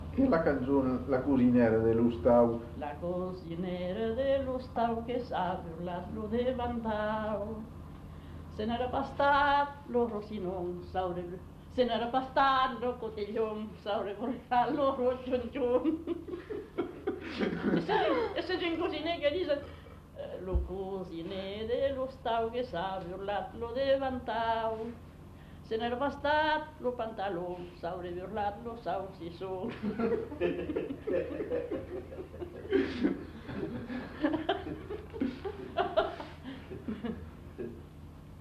Aire culturelle : Marsan
Lieu : Landes
Genre : chant
Effectif : 1
Type de voix : voix de femme
Production du son : chanté